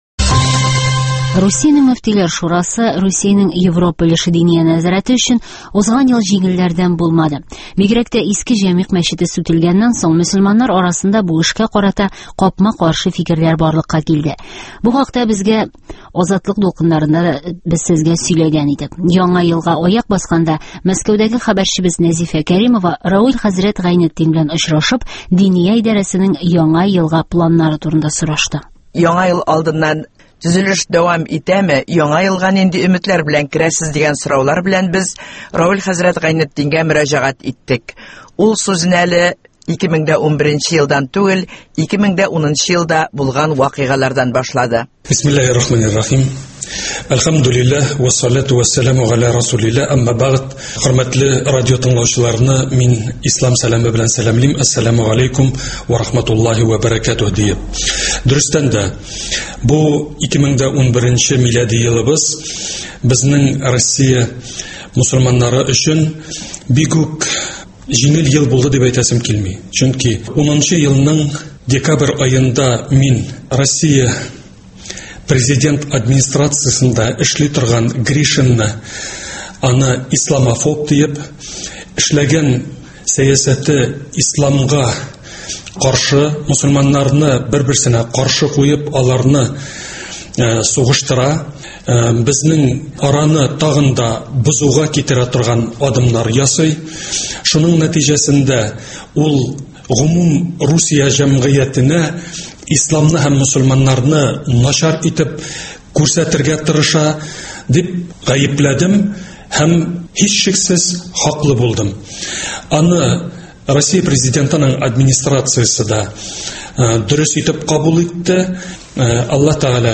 Равил хәзрәт Гайнетдин белән әңгәмә